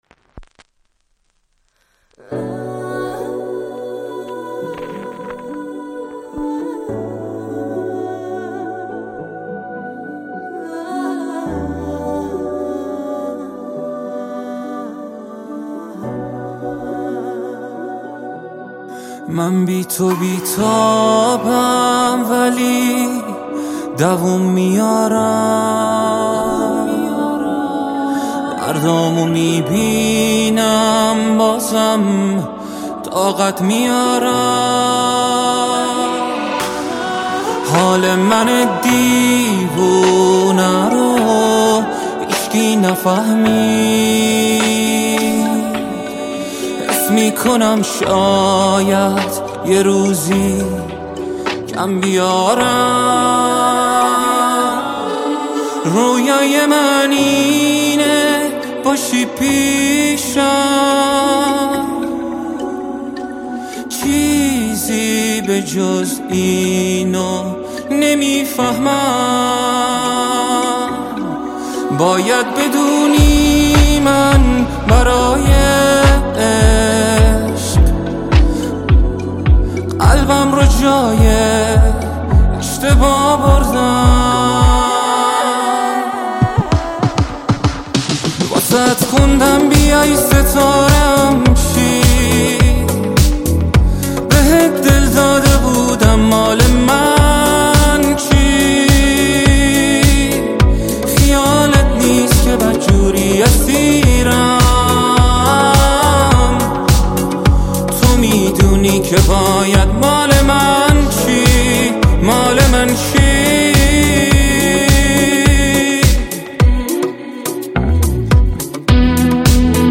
صدای دلنشینی داره واقعا